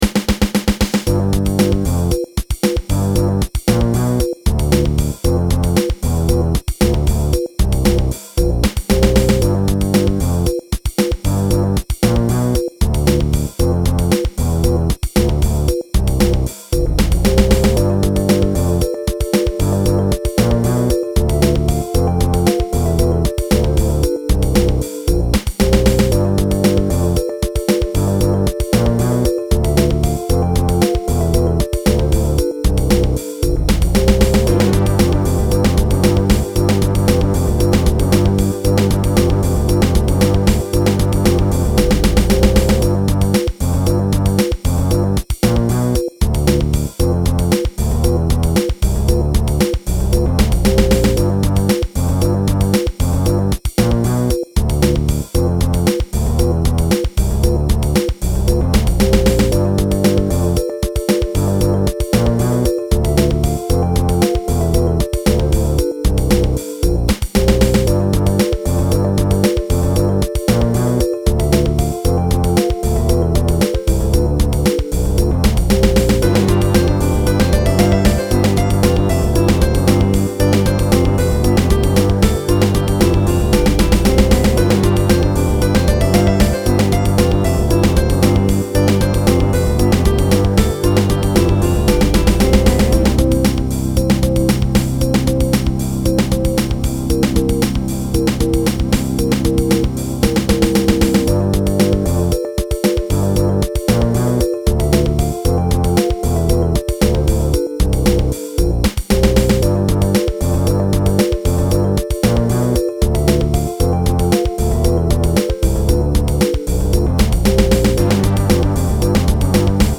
- (high bandwidth - stereo)
Play it loud.